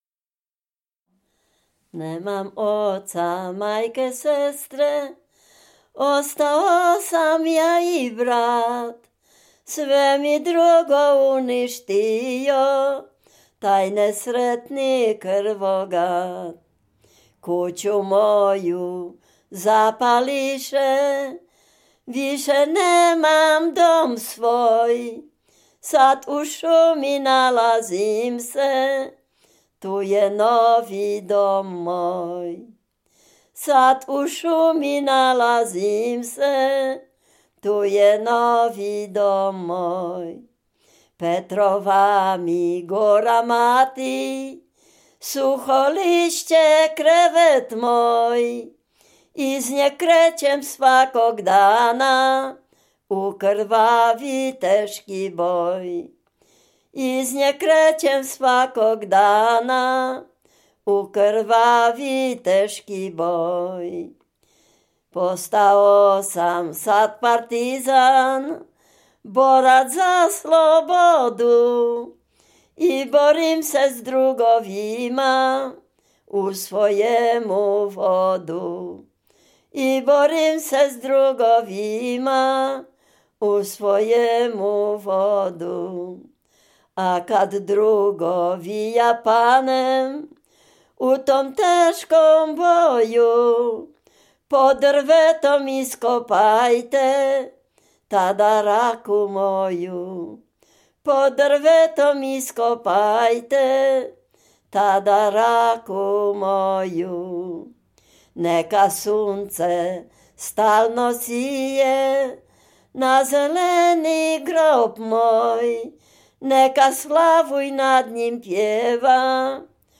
Dolny Śląsk, powiat bolesławiecki, gmina Nowogrodziec, wieś Zebrzydowa
rekruckie wojenkowe